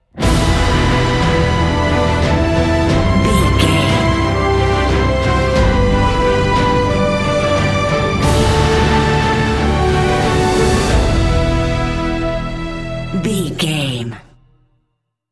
Uplifting
Aeolian/Minor
brass
orchestra
percussion
strings